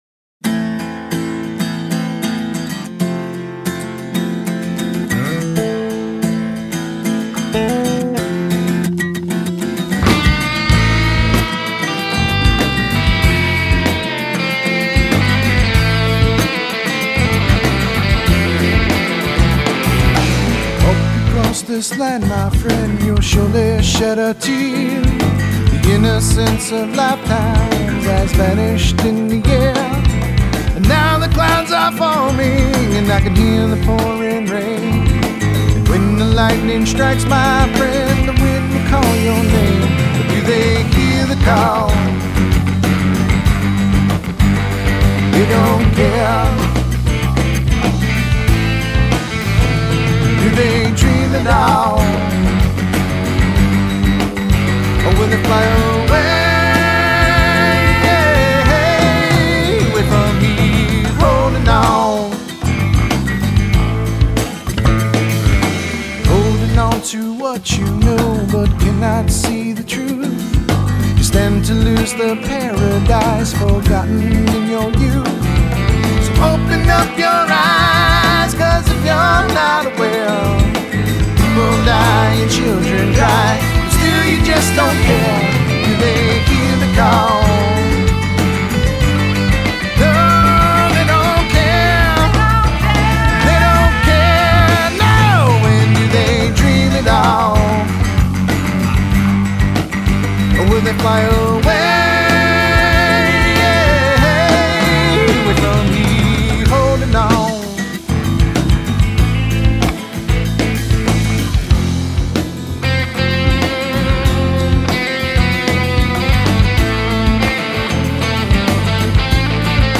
guitarist/vocalist
passionate vocal and percussive guitar style